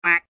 AV_duck_short.ogg